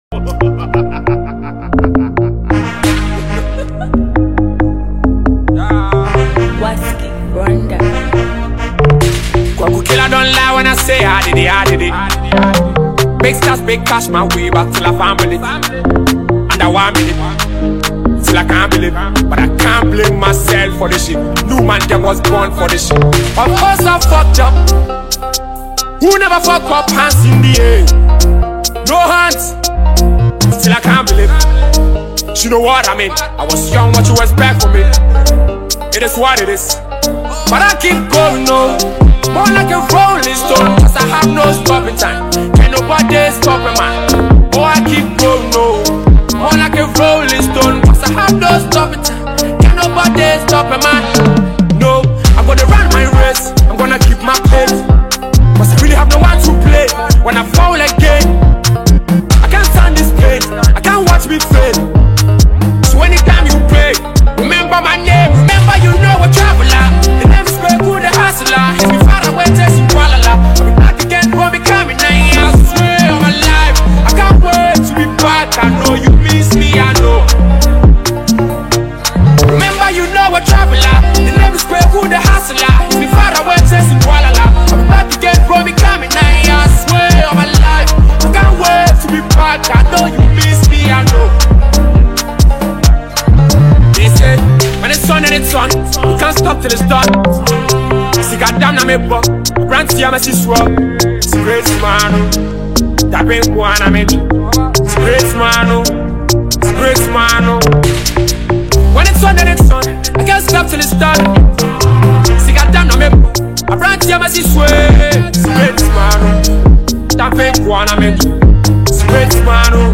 it has a fast-tempo vibe